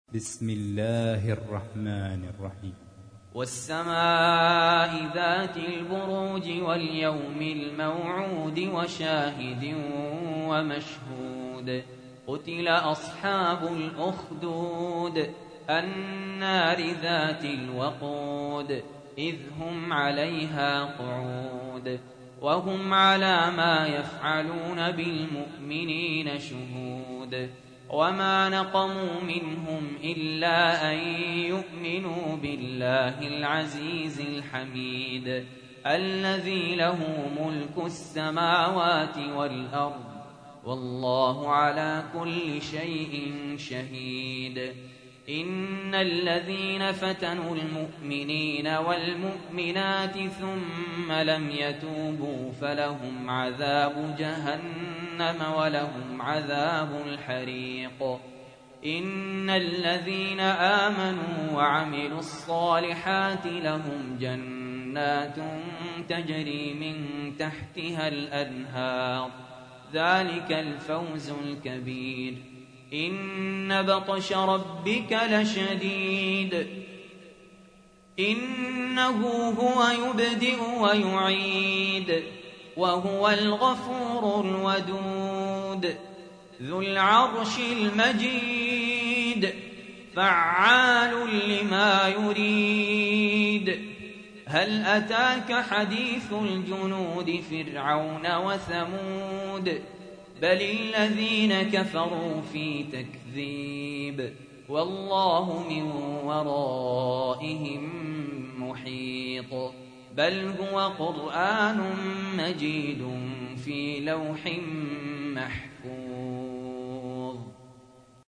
تحميل : 85. سورة البروج / القارئ سهل ياسين / القرآن الكريم / موقع يا حسين